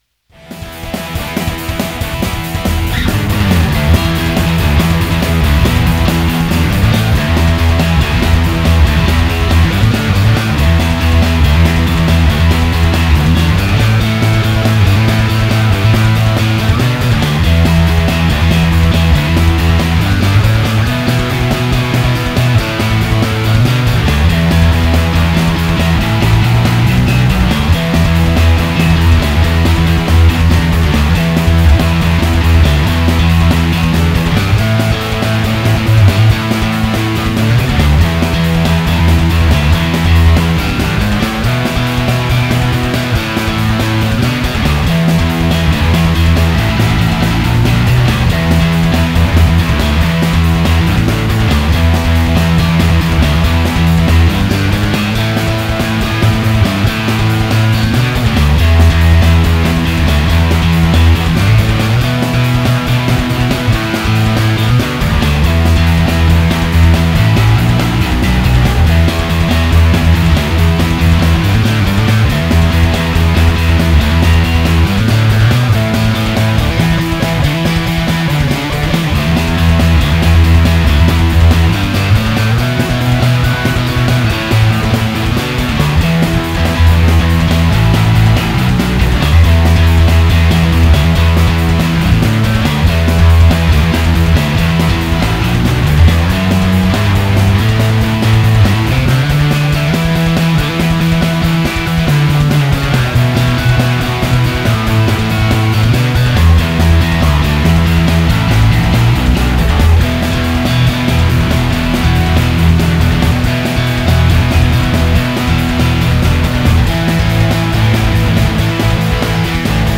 N Rick würde in meine Sammlung wirklich gut passen und wäre auch was feines, wenn ich aber höre, was der Gröwler mit ein bisschen Zerre da raushaut.....meine Herren !
Kurze Hörprobe Anhang anzeigen 901230 Anhang anzeigen 901231